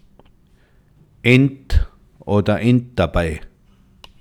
ent, entabei / Begriff-ABC / Mundart / Tiroler AT / Home - Tiroler Versicherung
Reith im Alpbachtal